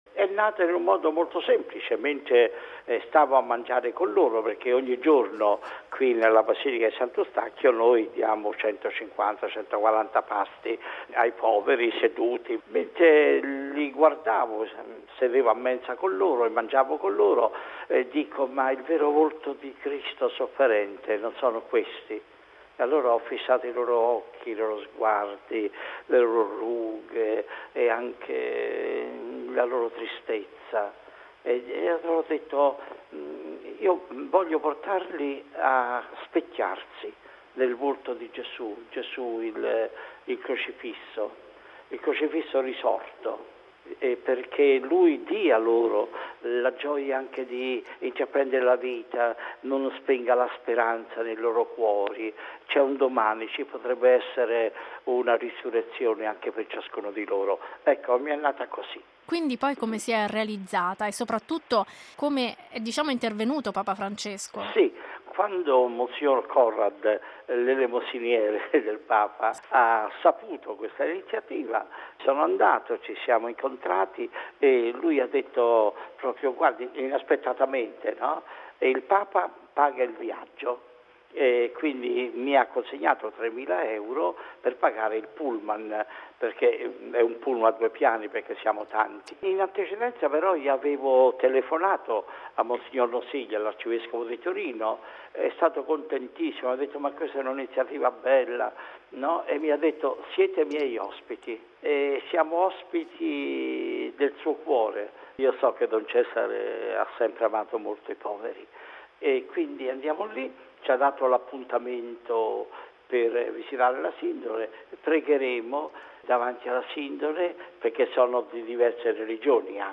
Bollettino Radiogiornale del 11/06/2015